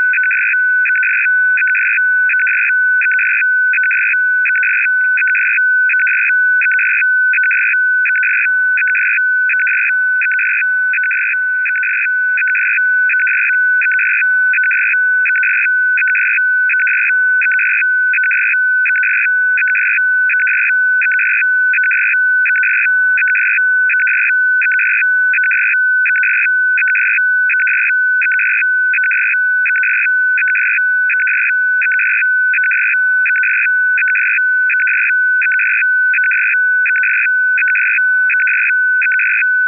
Recordings of digital numbers stations